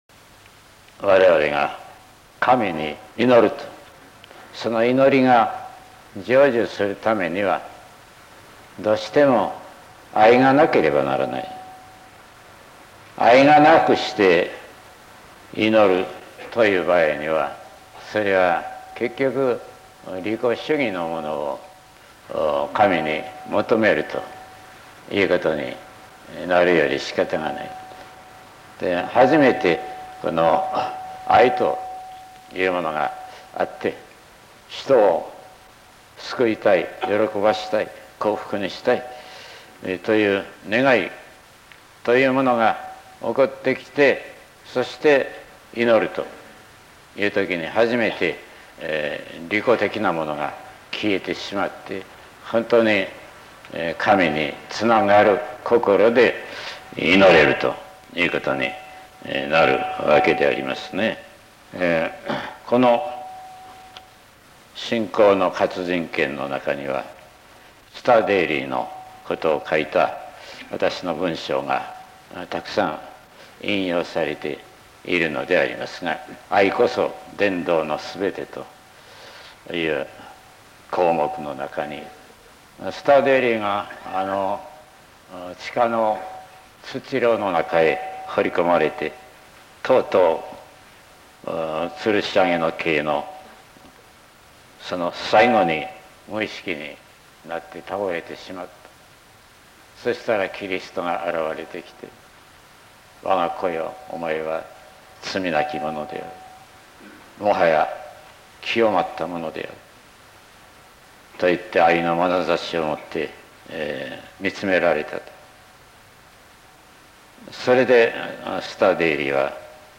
右をクリックしていただくと →朗読音声 ベートーヴェンのヴァイオリン協奏曲をバックに、朗読の音声が聞けます。